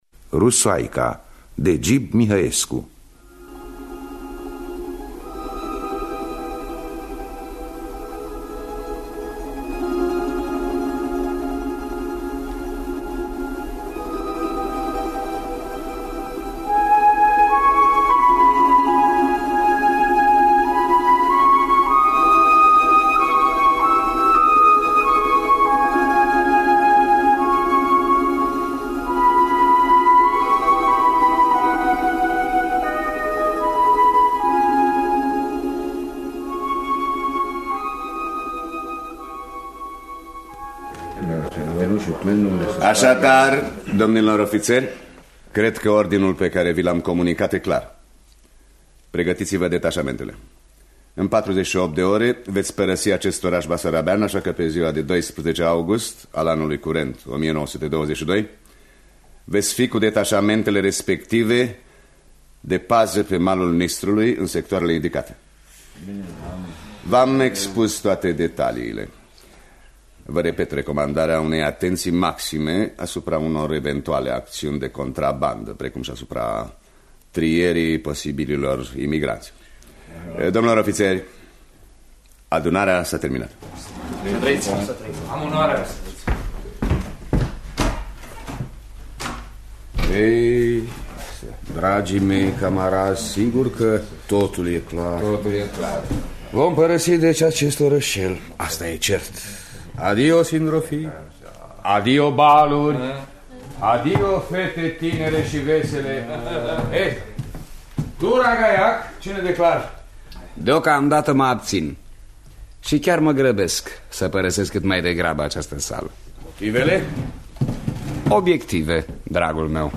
Dramatizarea radiofonică